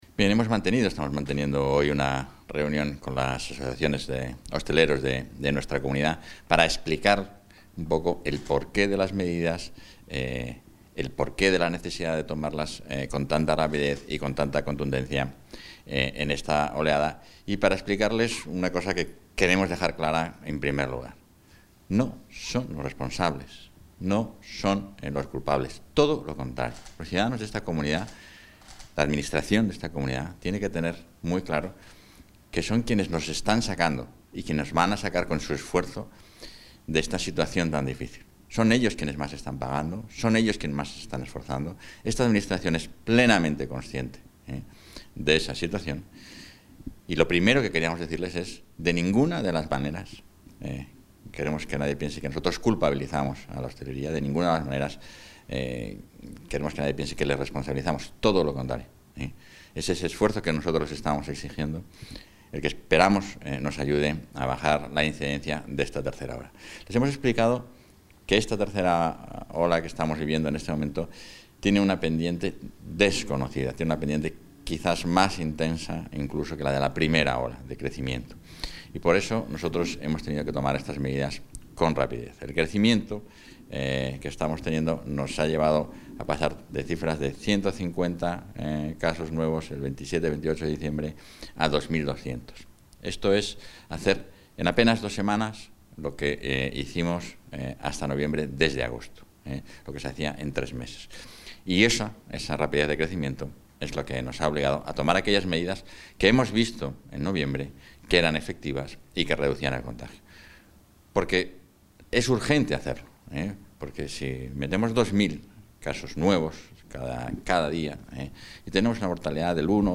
Valoración del vicepresidente de la Junta.